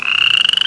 Toad Sound Effect
toad.mp3